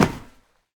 RunMetal3.ogg